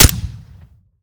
weap_mike_sup_fire_plr_01.ogg